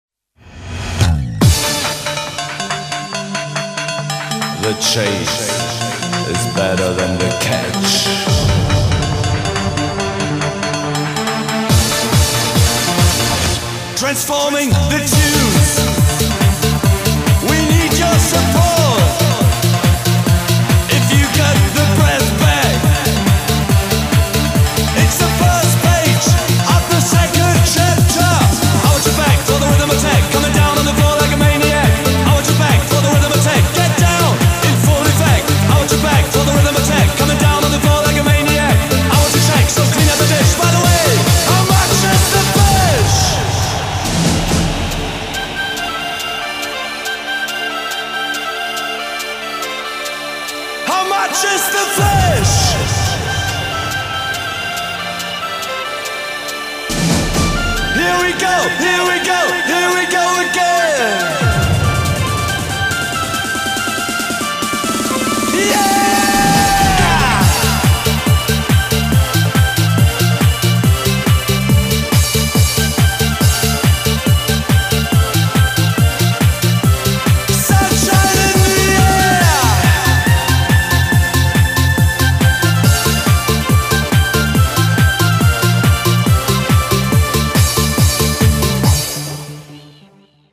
BPM140